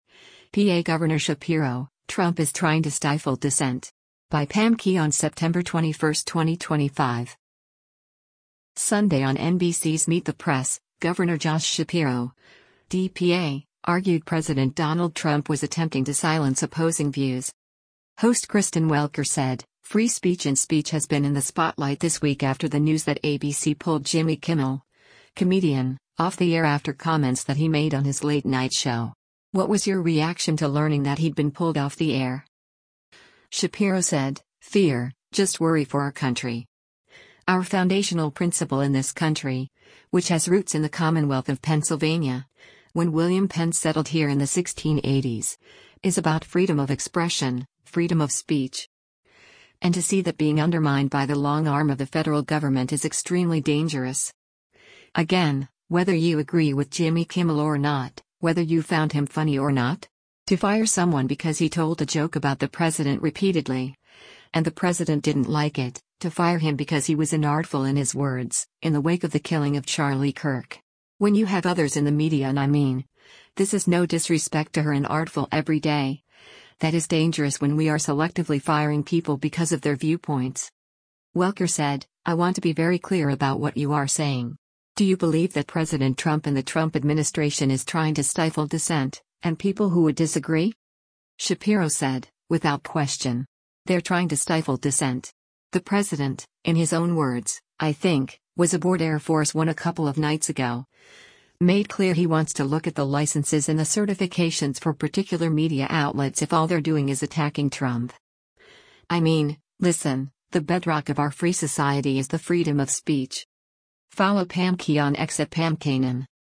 Sunday on NBC’s “Meet the Press,” Gov. Josh Shapiro (D-PA) argued President Donald Trump was attempting to silence opposing views.